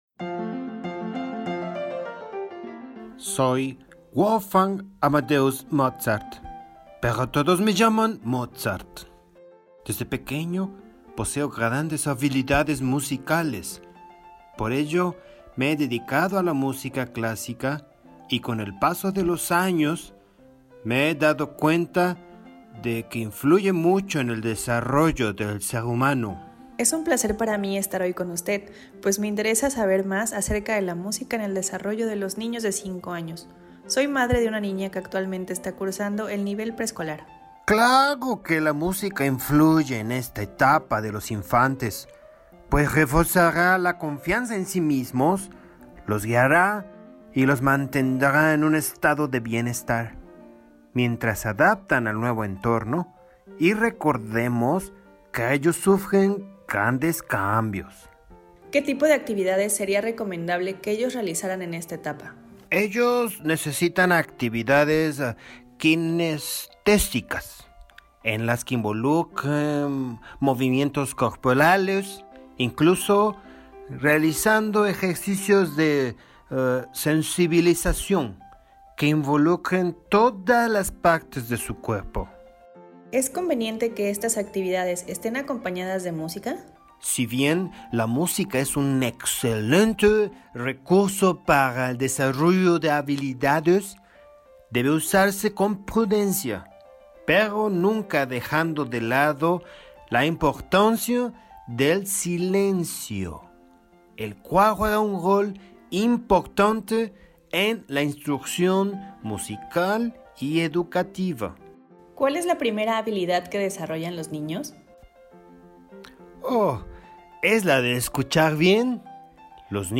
Ahora, haga clic sobre el icono para escuchar la entrevista, misma que le ayudará a comprender este tema y a realizar las actividades marcadas para este módulo:
Audio_Entrevista-mama-a-Mozart.mp3